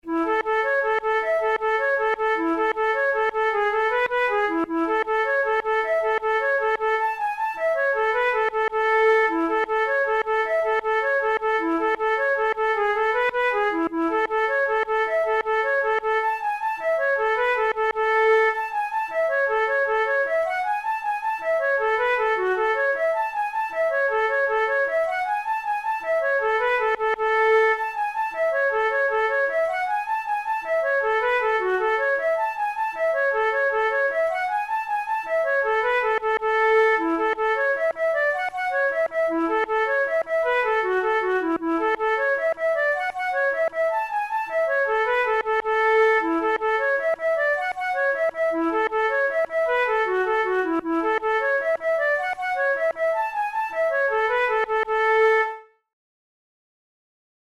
InstrumentationFlute solo
KeyA major
Time signature6/8
Tempo104 BPM
Jigs, Traditional/Folk
Traditional Irish jig